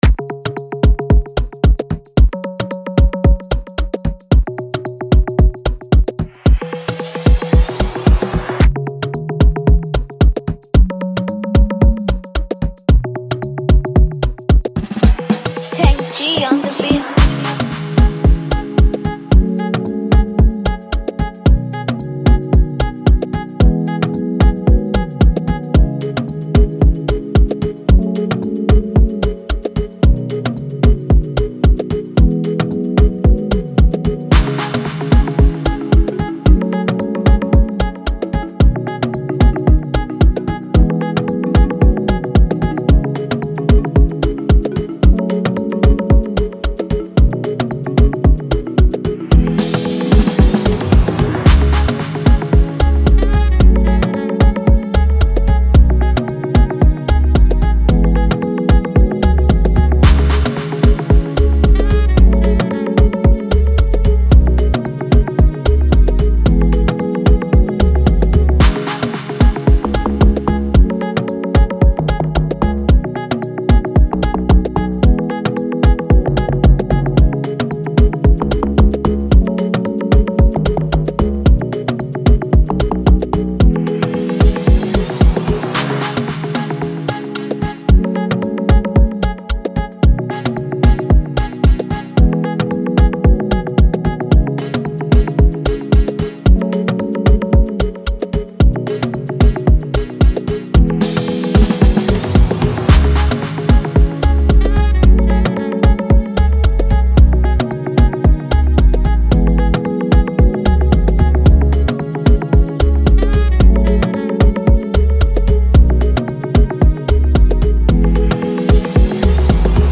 Get ready to groove to the Nigerian cool rhythm
a scintillating Afrobeat instrumental